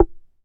8166 Board Move Or Place Object C 03 0s 0.01 MB